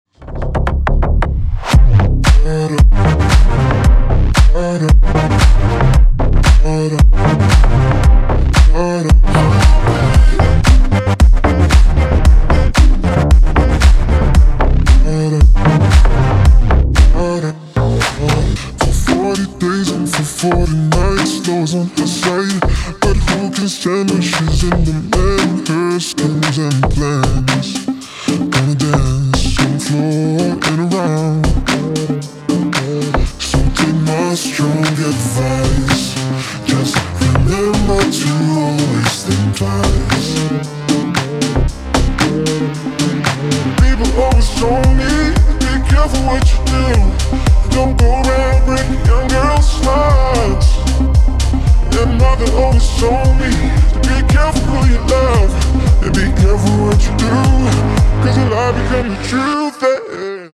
remix
deep house
future house
басы
цикличные
Клубный ремикс